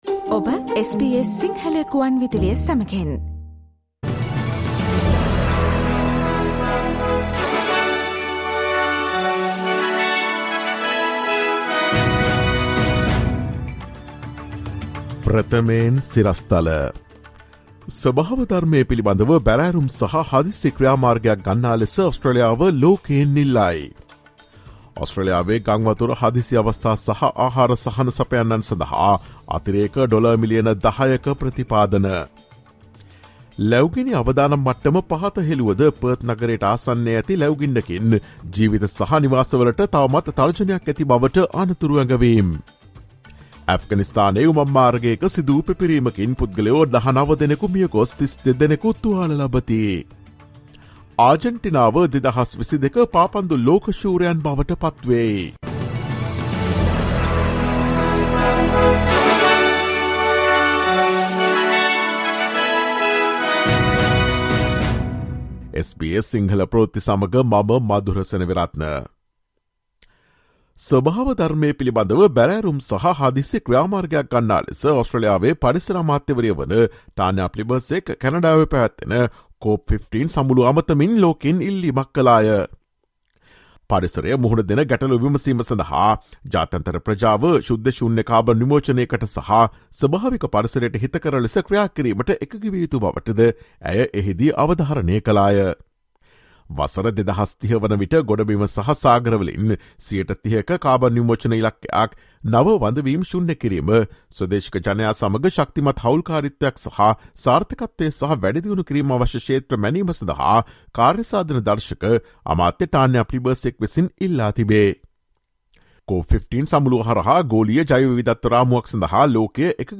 Listen to the latest news from Australia, across the globe, and the latest news from the sports world on SBS Sinhala radio news – Monday, 19 December 2022.